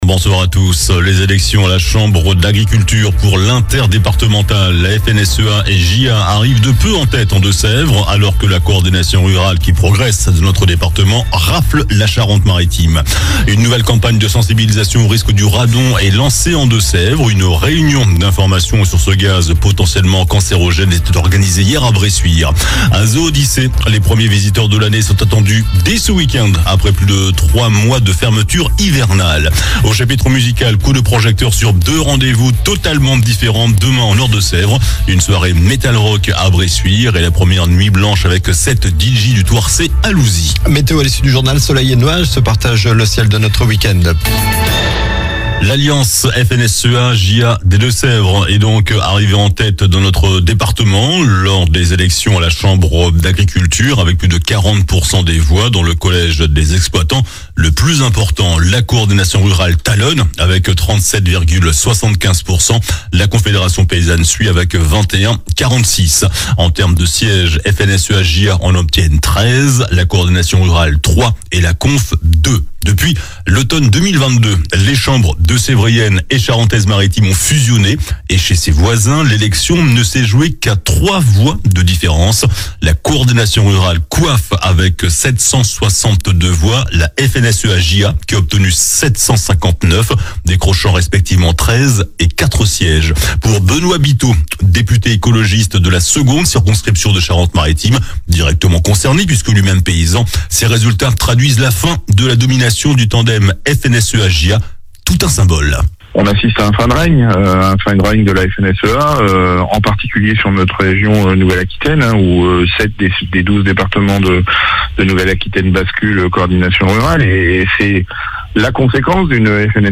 JOURNAL DU VENDREDI 07 FEVRIER ( SOIR )